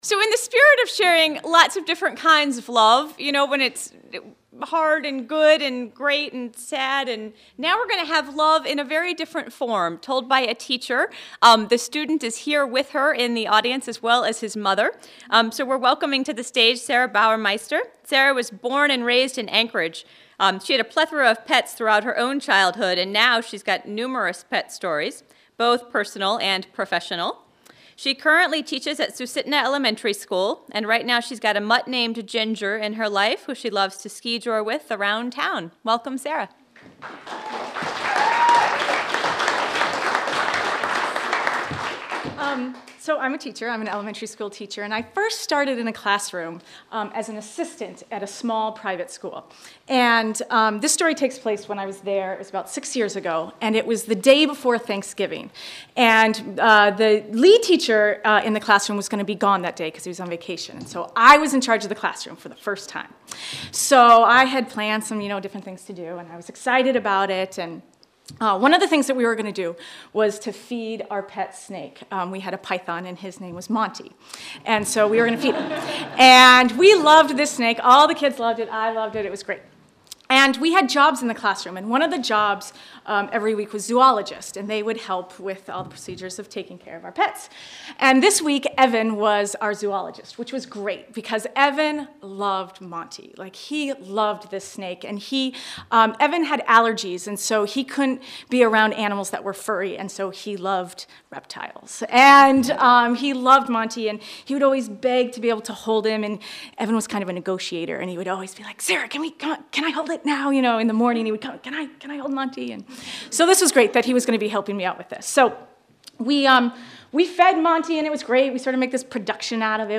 Listen to selected stories from our 2011 Valentine’s Day show: